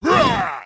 Barbarian King Attack Clash Of Clans Sound Effect Free Download